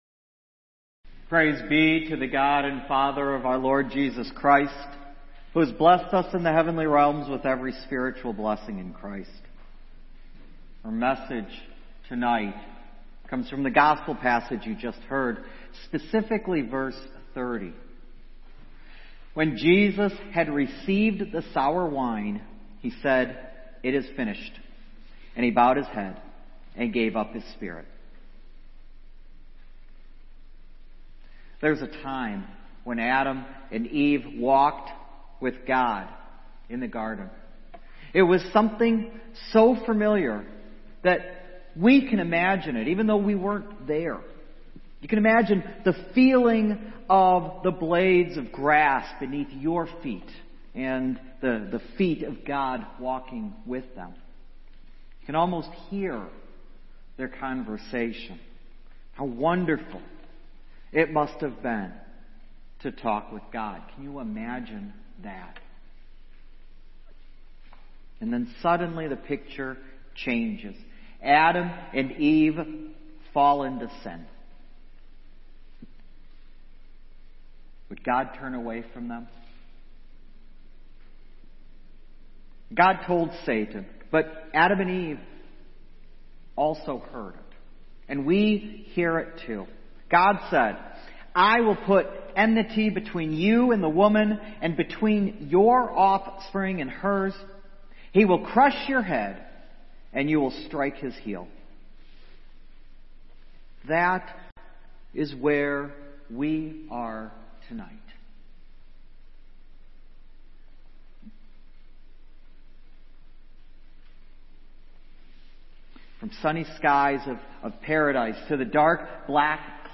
What an uplifting sermon.